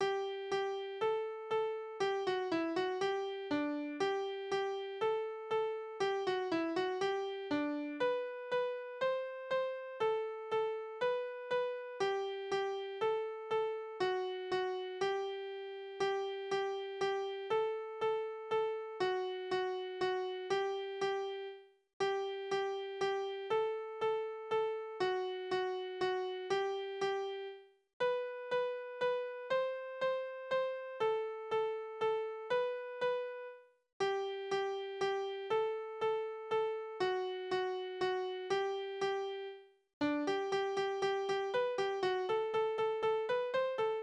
Tanzverse: Amor auf Jagd
Tonart: G-Dur
Taktart: 4/4, 3/4, 4/8
Tonumfang: Septime
Besetzung: vokal